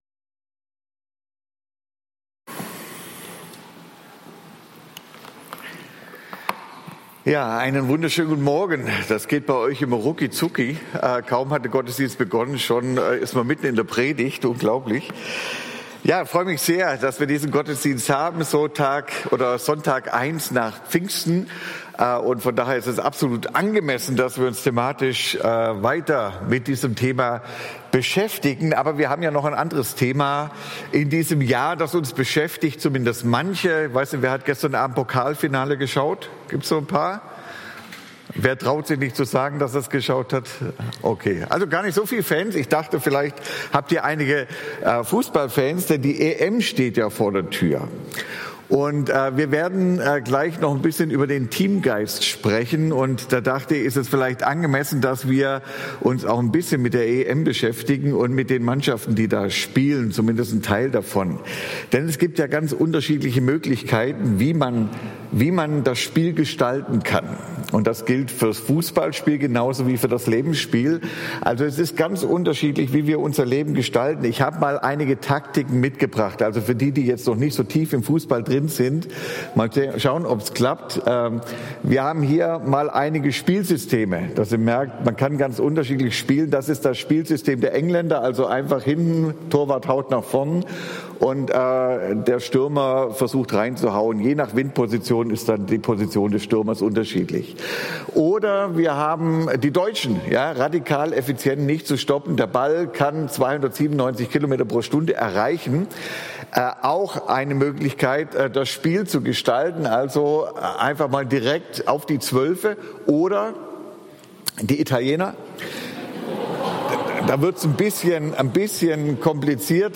Predigt-Podcast